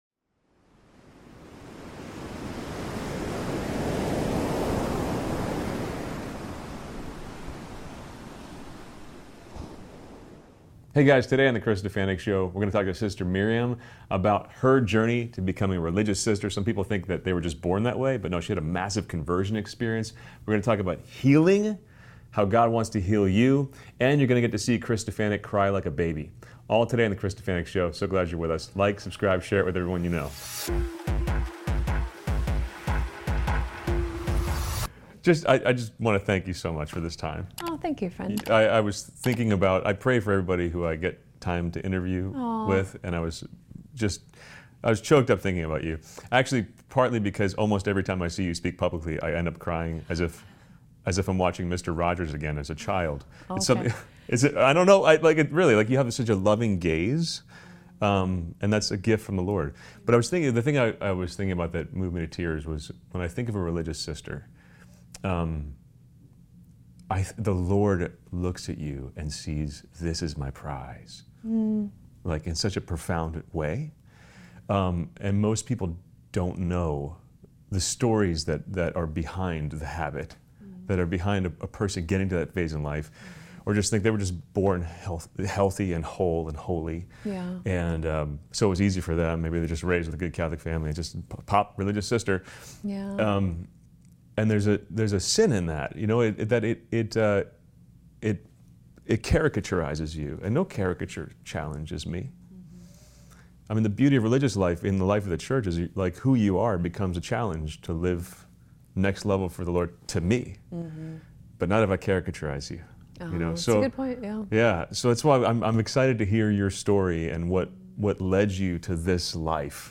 SPECIAL THANKS TO CORPORATE TRAVEL, PRODUCERS OF THE GOOD NEWS CONFERENCE FOR MAKING THIS INTERVIEW POSSIBLE!